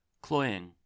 Ääntäminen
IPA : /ˈklɔɪ.ɪŋ/